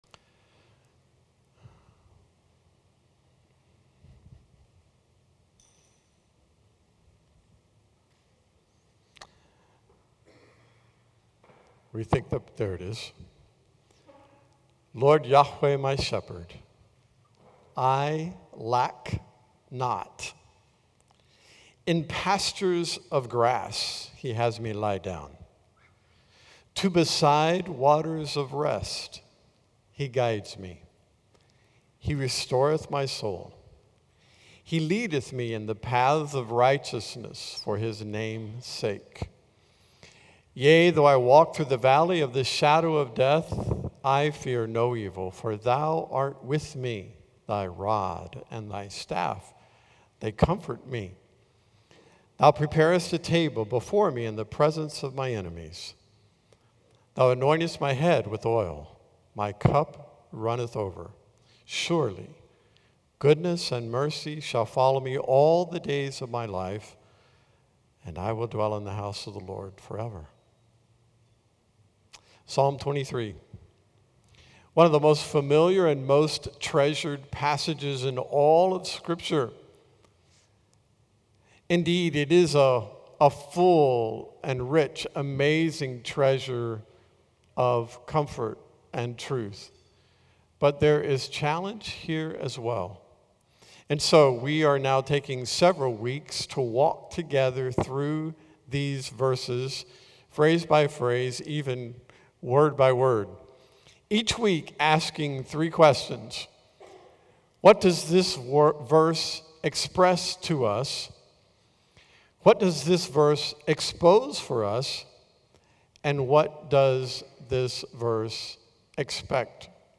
A message from the series "my Shepherd ."